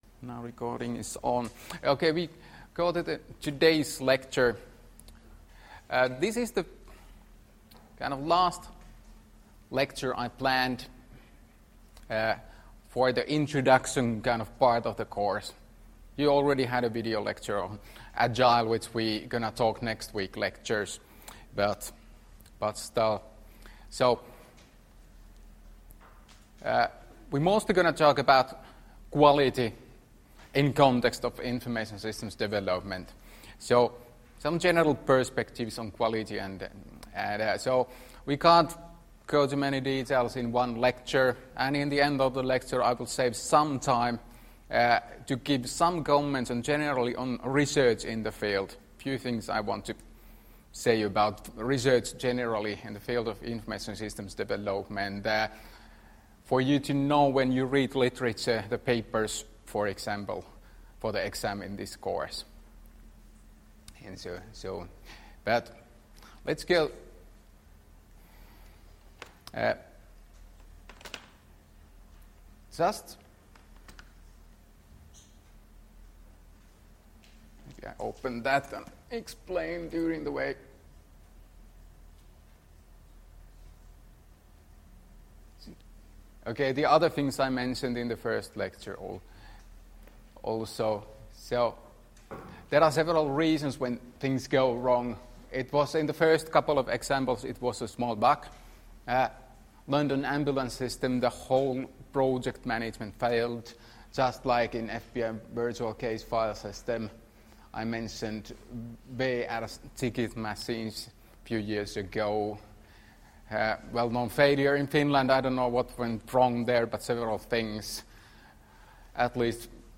Luento 25.1.2018 — Moniviestin